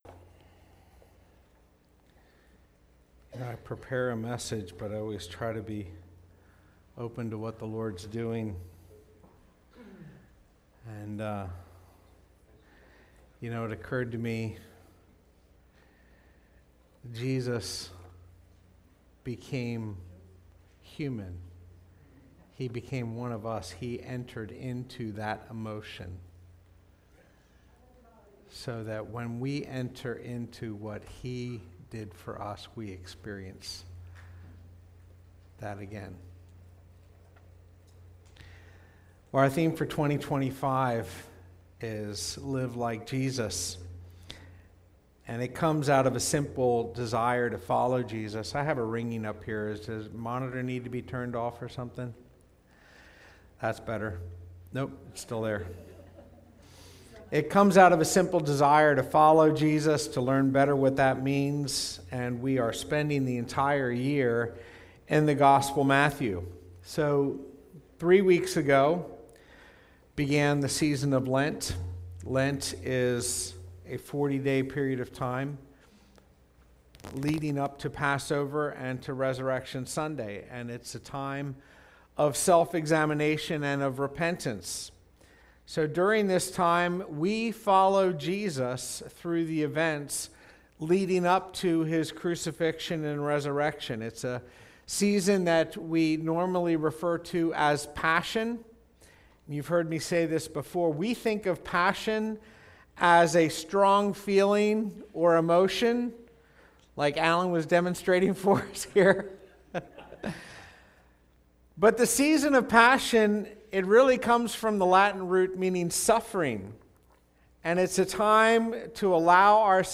Sermons | Spring City Fellowship